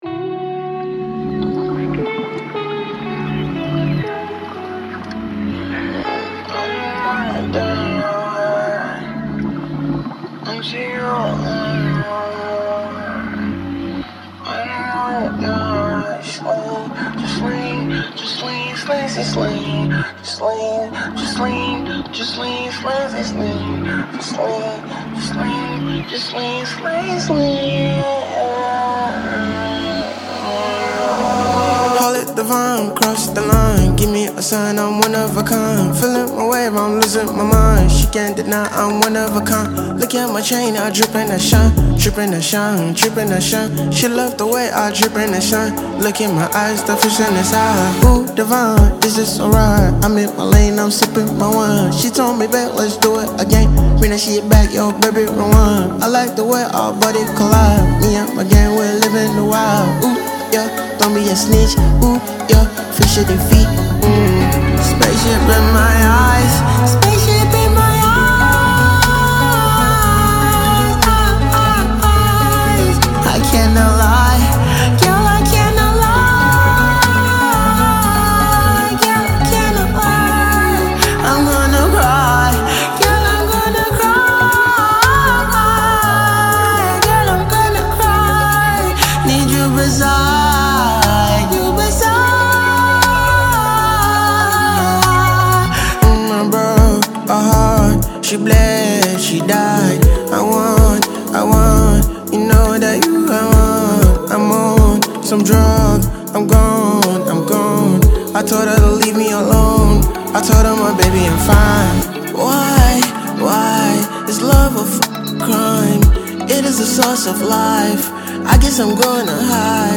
dance single